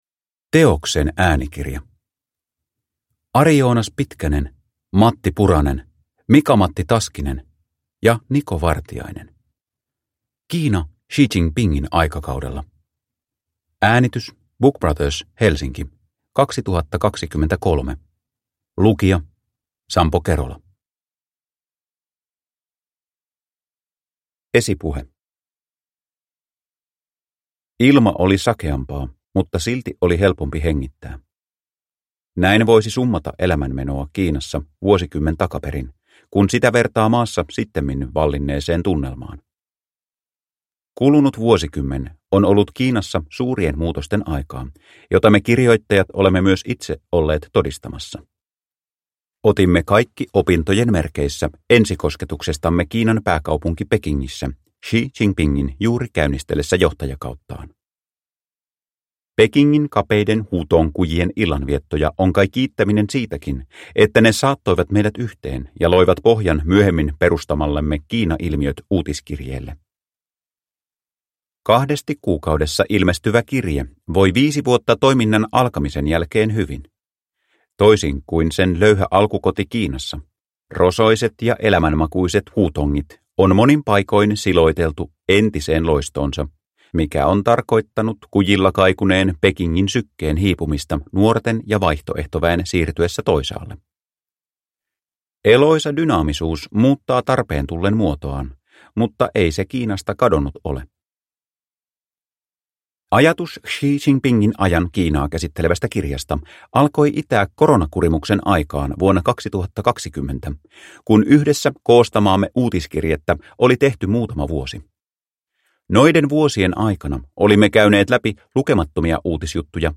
Kiina Xi Jinpingin aikakaudella – Ljudbok – Laddas ner